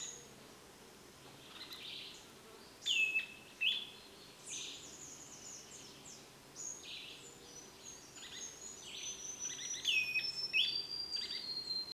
Bailarín Oliváceo (Schiffornis virescens)
Fase de la vida: Adulto
Localidad o área protegida: Parque Provincial Urugua-í
Condición: Silvestre
Certeza: Vocalización Grabada
bailarin-olivaceo.mp3